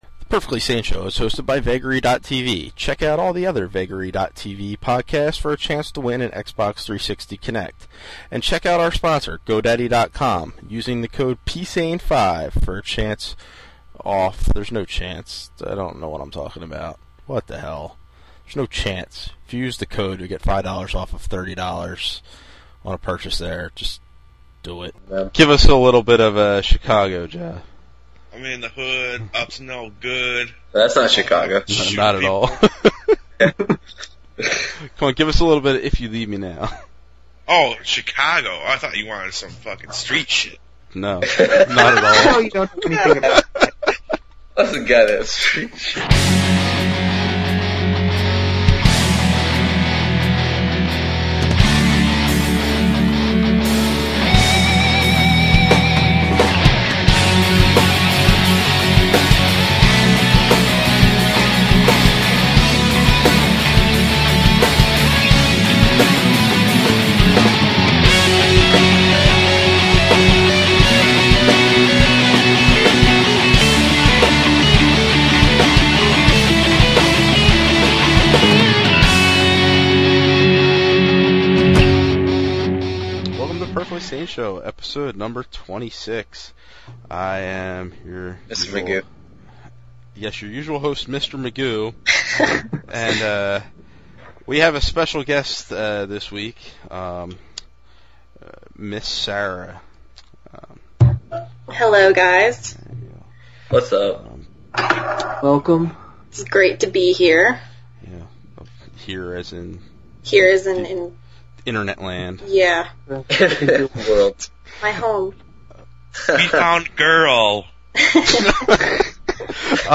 This episode, like all our classic episodes, appears unedited except for some music we don’t have (and never had) the license too (and Spotify makes us edit out now).